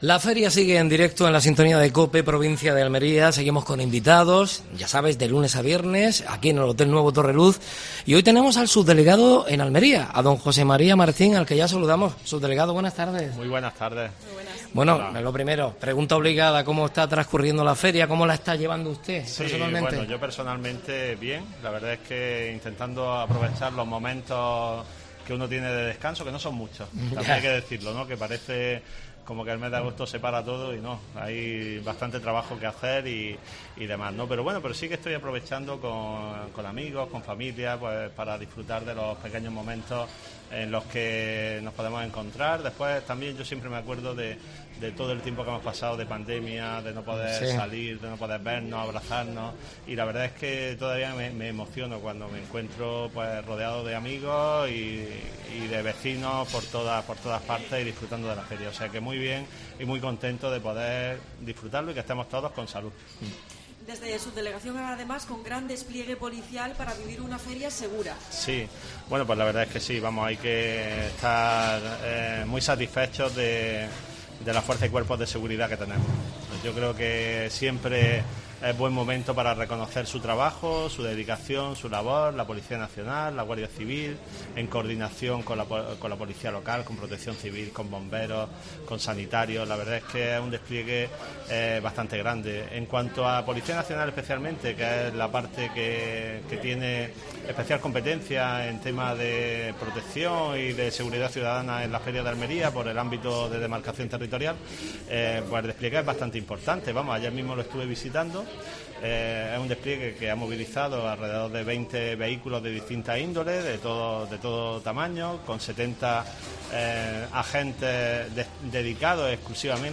AUDIO: Entrevista desde el Hotel Torreluz a José María Martín (subdelegado del Gobierno central en Almería).